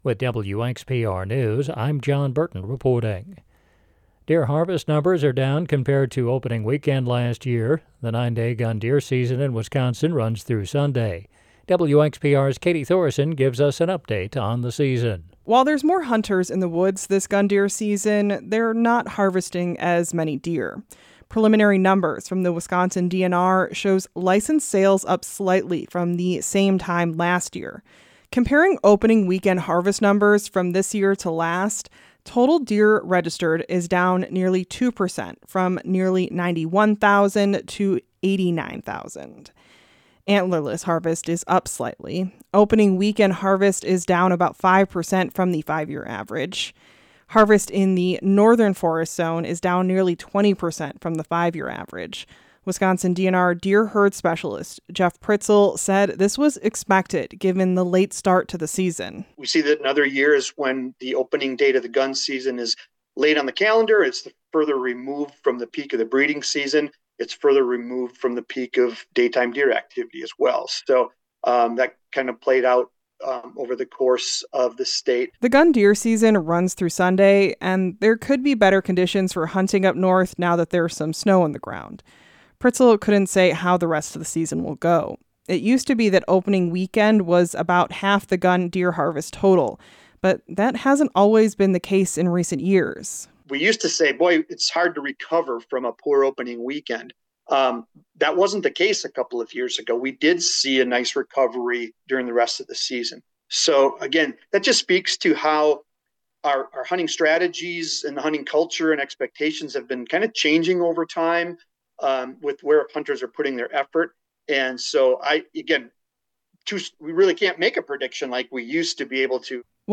The Extra is WXPR’s daily news podcast. Get the news you need to stay informed about your local community – all in six minutes or less.